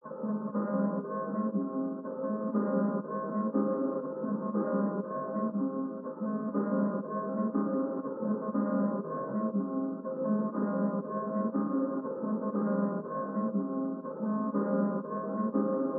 EN - Hard II (120 BPM).wav